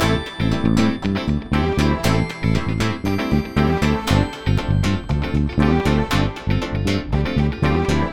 28 Backing PT2.wav